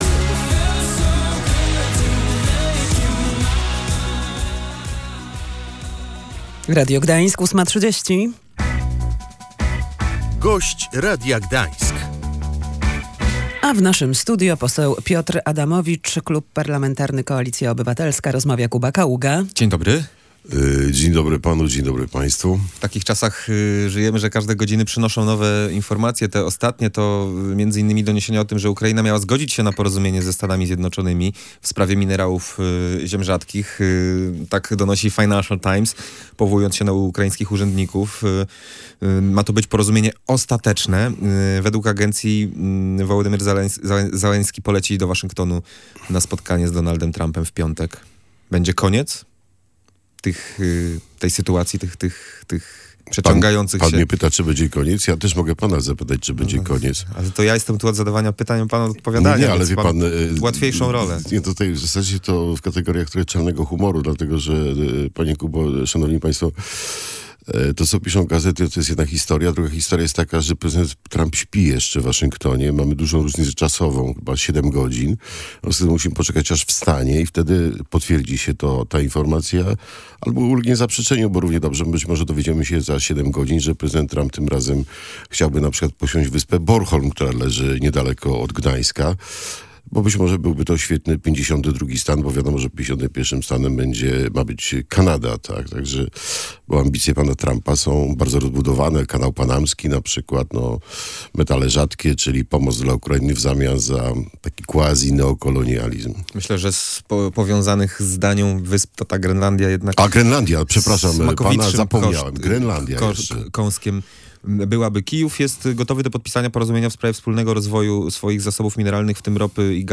Nie może być pokoju na Ukrainie bez gwarancji bezpieczeństwa. Jednak prezydent Stanów Zjednoczonych patrzy na sytuację w Europie przez pryzmat interesów USA – mówił gość Radia Gdańsk, poseł Koalicji Obywatelskiej Piotr Adamowicz.